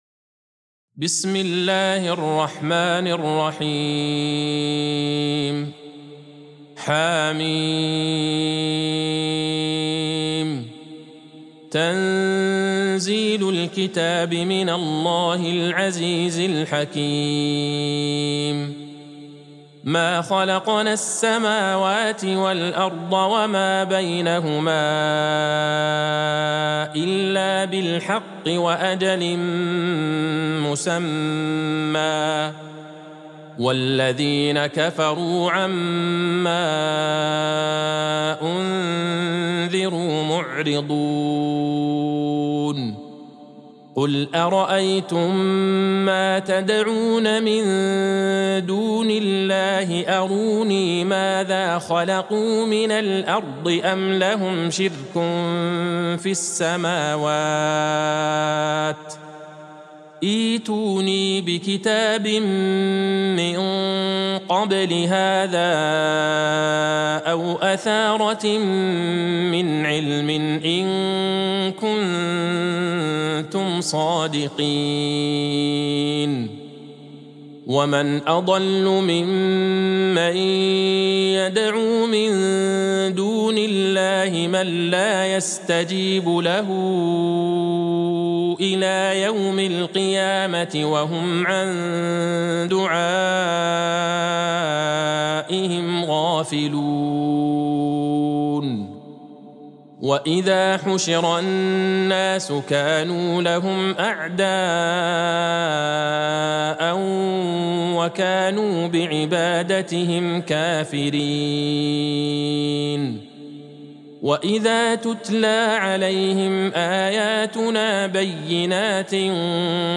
سورة الأحقاف Surat Al-Ahqaf | مصحف المقارئ القرآنية > الختمة المرتلة ( مصحف المقارئ القرآنية) للشيخ عبدالله البعيجان > المصحف - تلاوات الحرمين